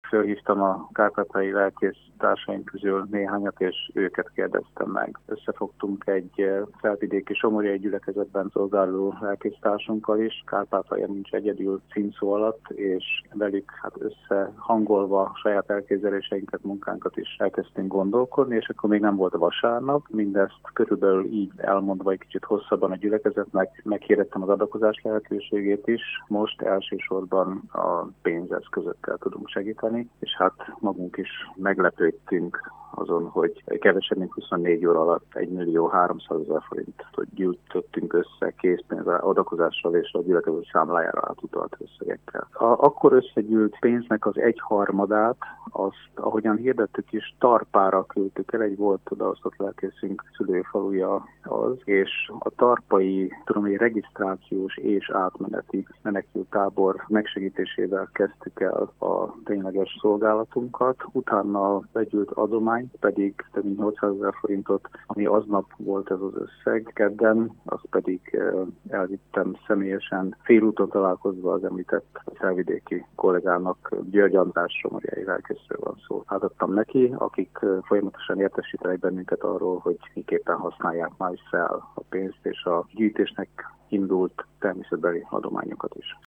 riport_hirbe_13.mp3